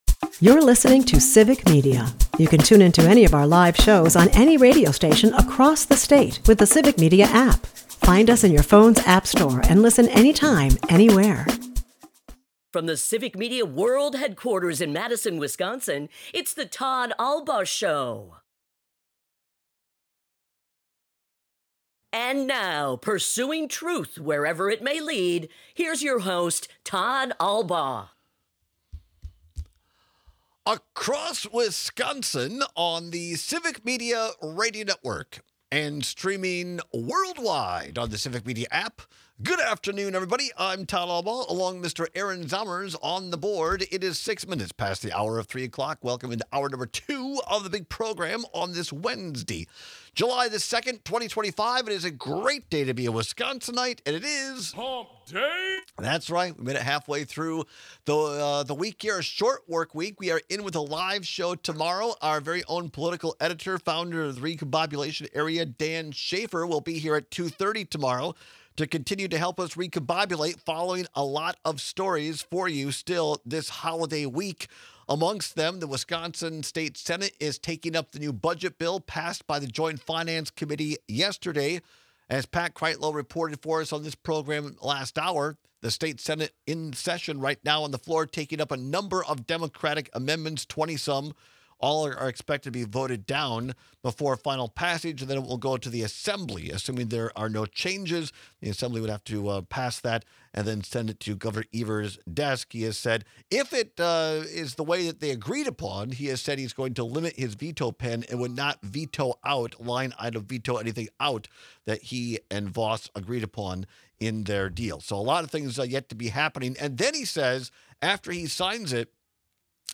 While both are amazing, we take your calls and texts on which one doesn’t always live up to Summer celebration standards. Are you a cheap date?